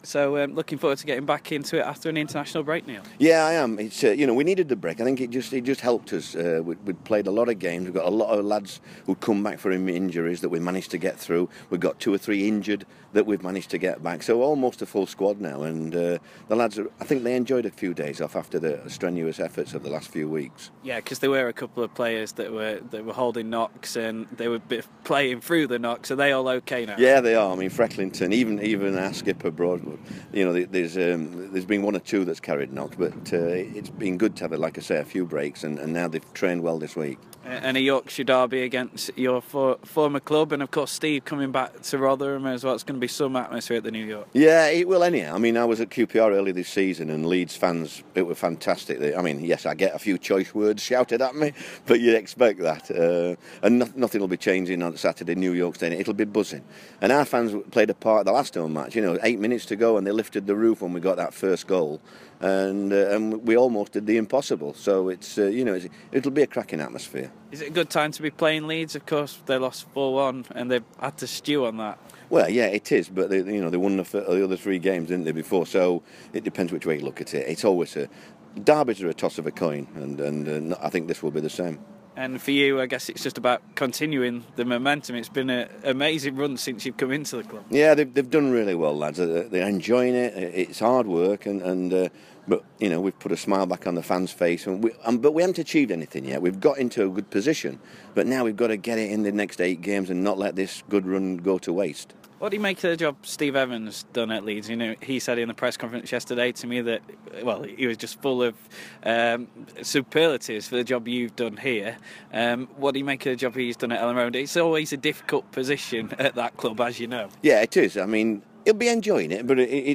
Rotherham United boss Neil Warnock speaks